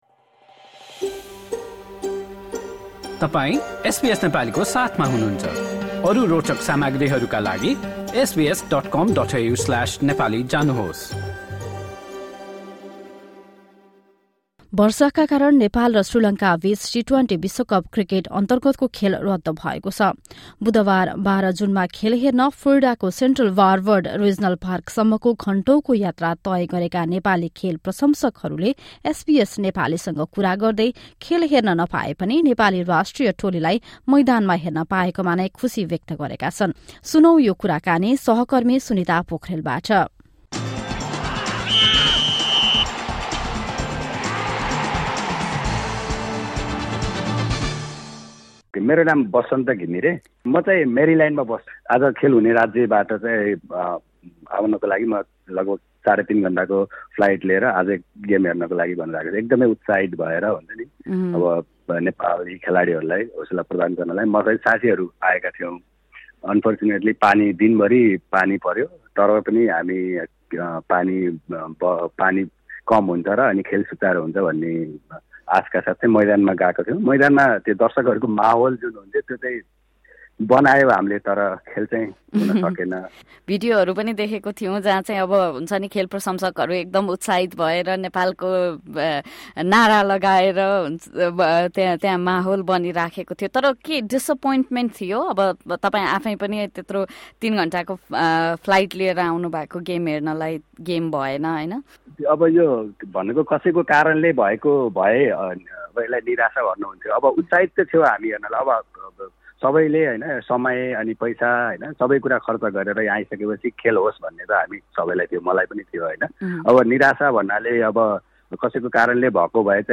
Some fans spoke to SBS Nepali about the excitement and vibe at the ground.